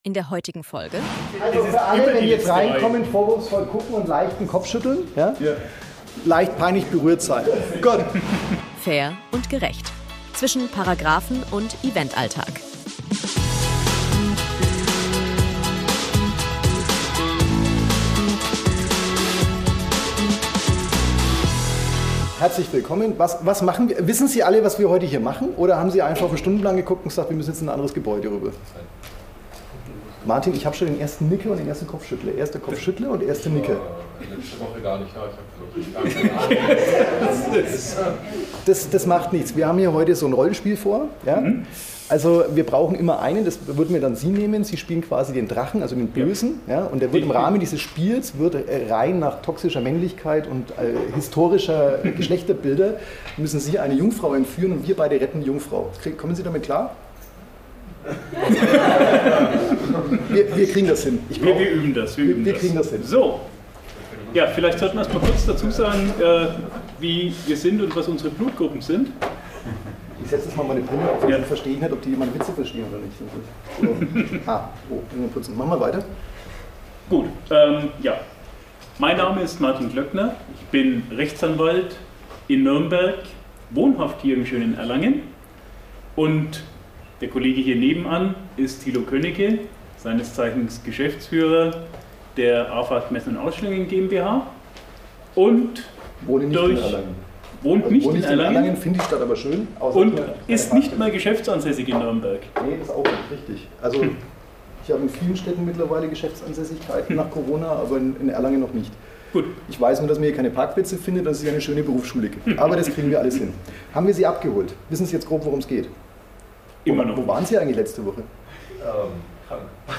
#11 Live in Erlangen (Sonderfolge) ~ Fair & Gerecht Podcast